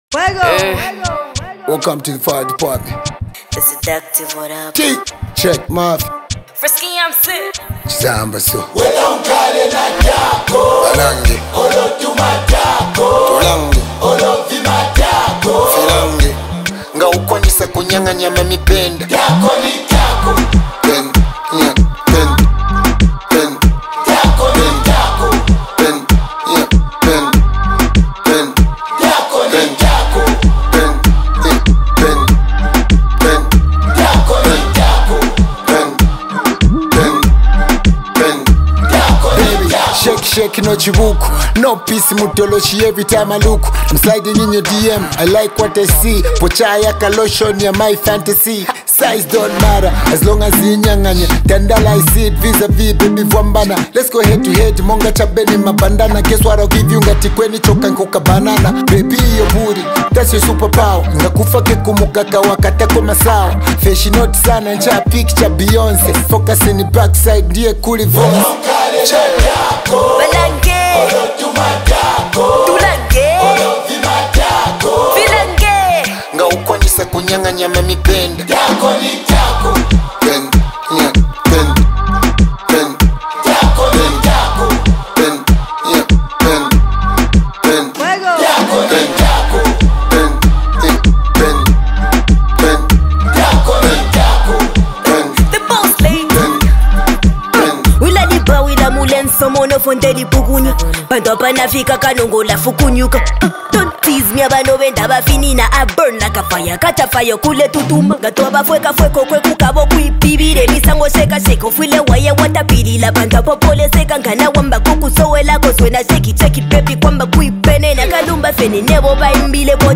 Zambian hip-hop
brings diverse styles and flows to the track.